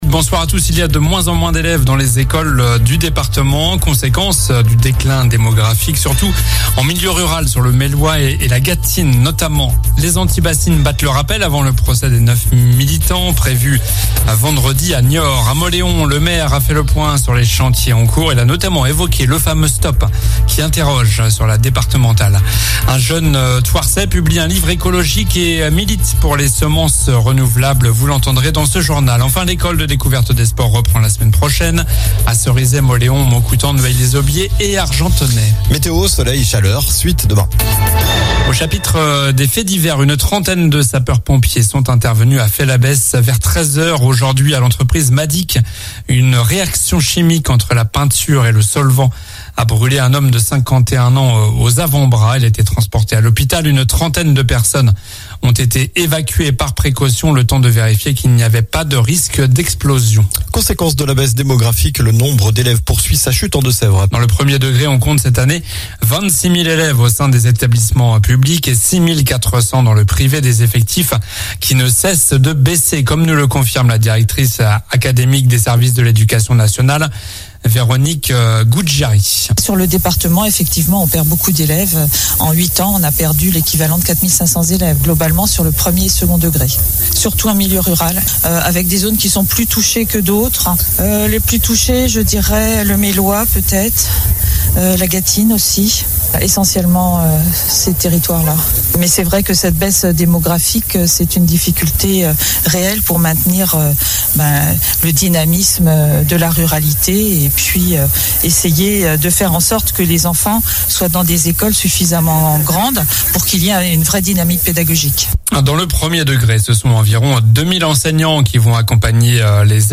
JOURNAL DU MARDI 05 SEPTEMBRE ( soir)